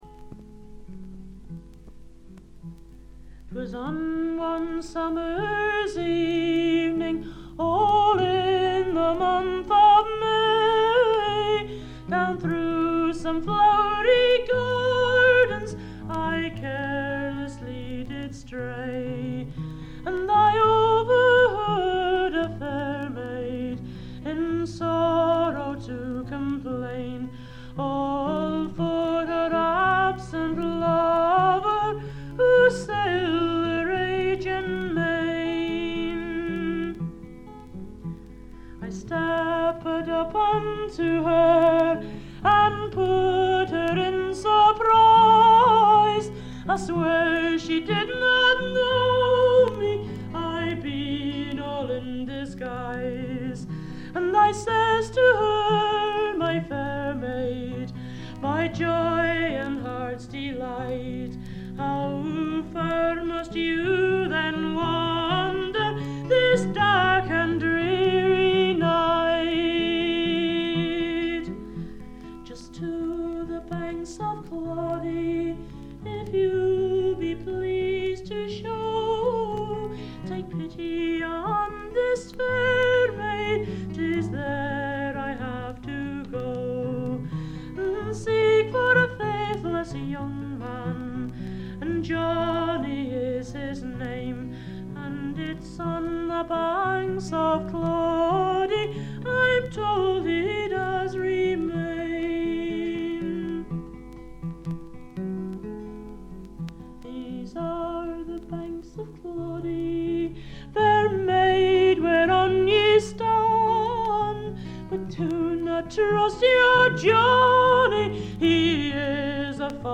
軽微なバックグラウンドノイズにチリプチ少々。
内容はギター弾き語りのライブで、全11曲のうち自作3曲、カヴァー1曲、残りがトラッドという構成です。
試聴曲は現品からの取り込み音源です。